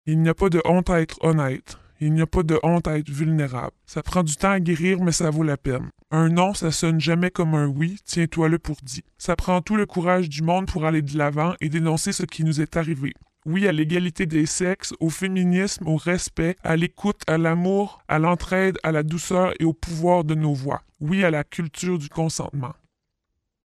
Message d’espoir d’une victime: